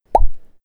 quickPop.wav